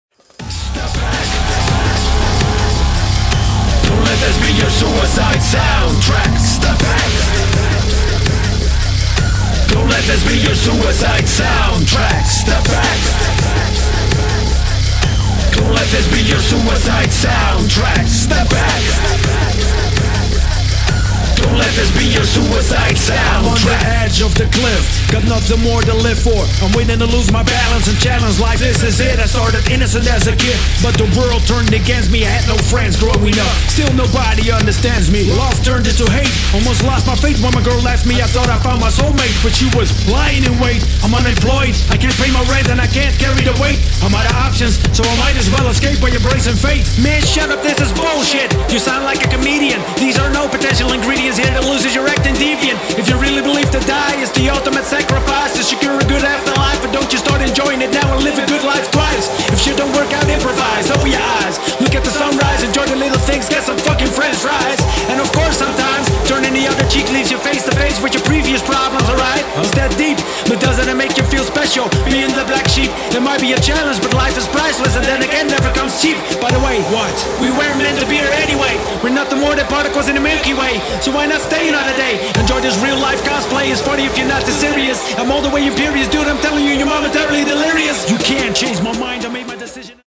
[ DUBSTEP / DRUMSTEP / DRUM'N'BASS ]